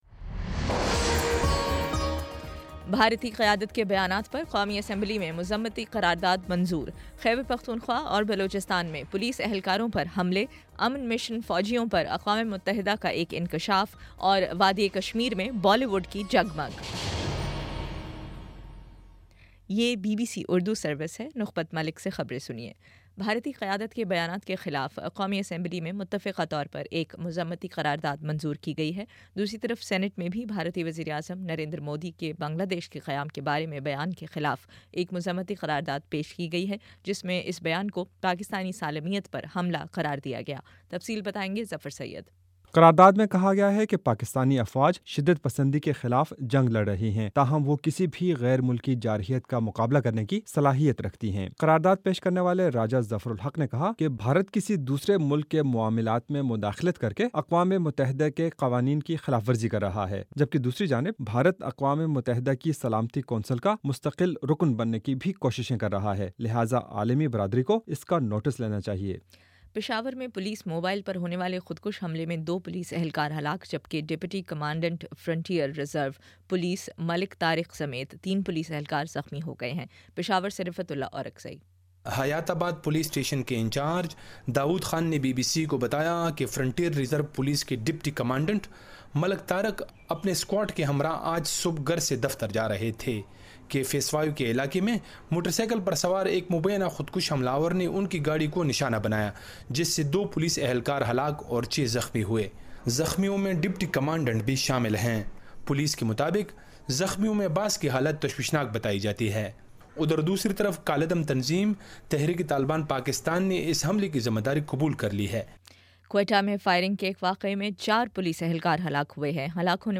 جون 11: شام پانچ بجے کا نیوز بُلیٹن